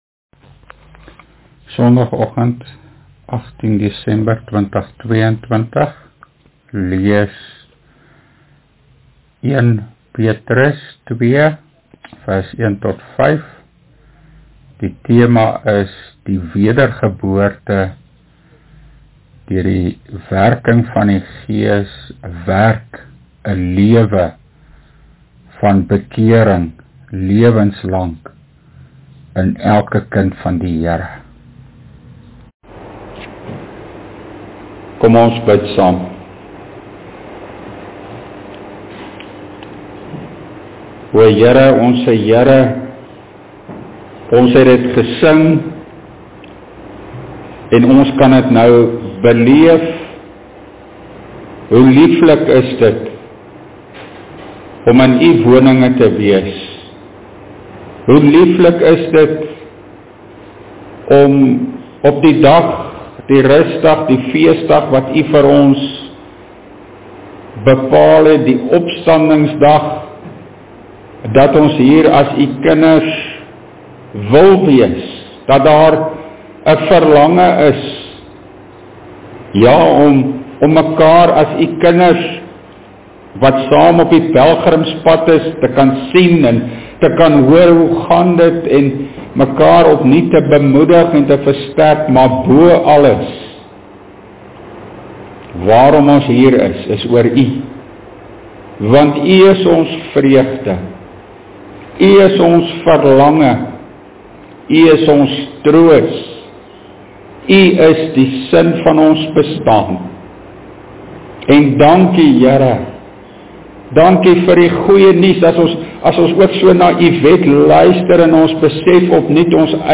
1 PETRUS PREKE: (8) Die lewenslange bekeringslewe as vrug van die wedergeboorte (1 Petrus 2:1-5)
Opname (GK Carletonville, 2022-12-18)